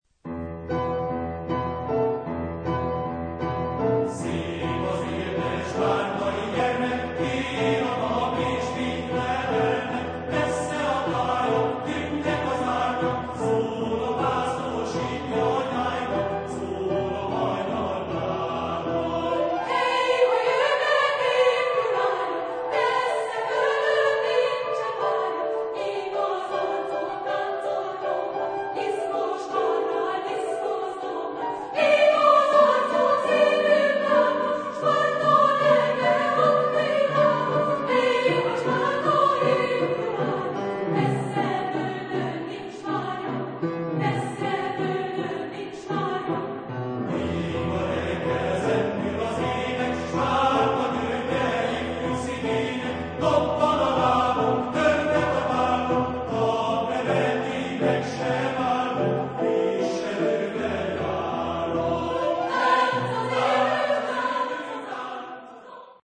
Genre-Style-Form: Secular ; Lyrical ; Choir
Type of Choir: SATB  (4 mixed voices )
Instruments: Piano (1)
Tonality: E aeolian